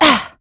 Valance-Vox_Landing.wav